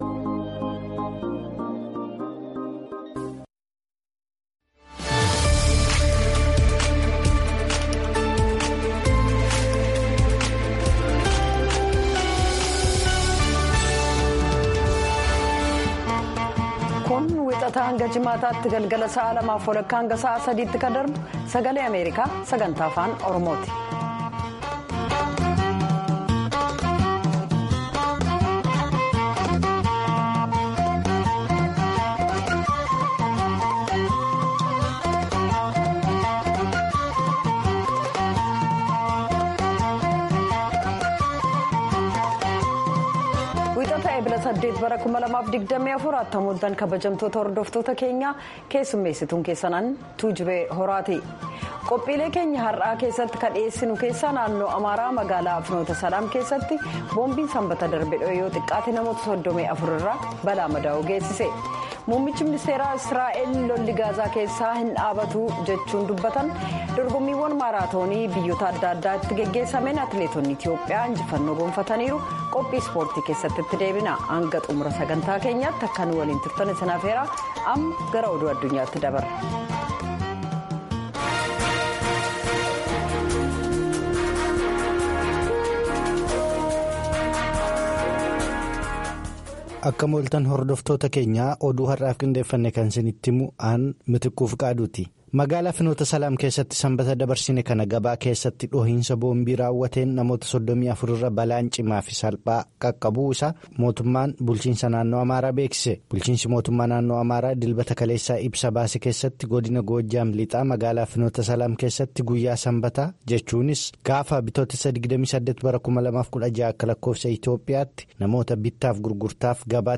Half-hour broadcasts in Afaan Oromoo of news, interviews with newsmakers, features about culture, health, youth, politics, agriculture, development and sports on Monday through Friday evenings at 8:30 in Ethiopia and Eritrea.